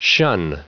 Prononciation du mot shun en anglais (fichier audio)
Prononciation du mot : shun